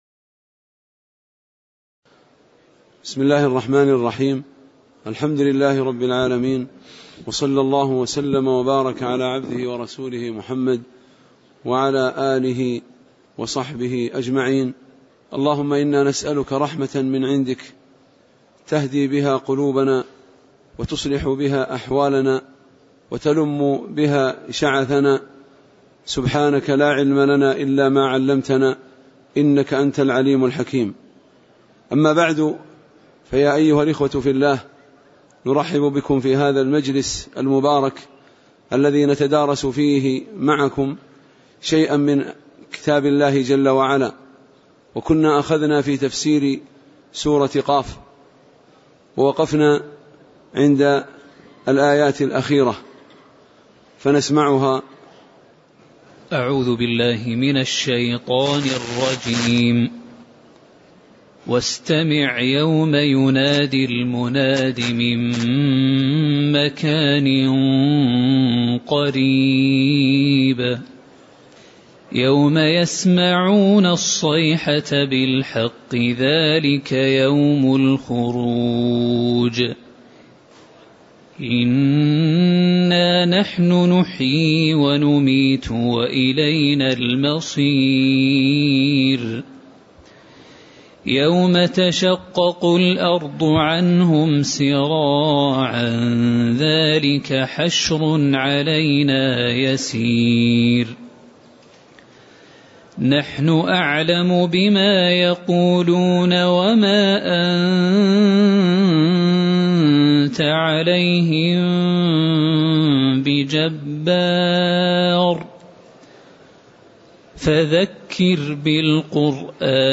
تاريخ النشر ٣٠ ربيع الثاني ١٤٣٩ هـ المكان: المسجد النبوي الشيخ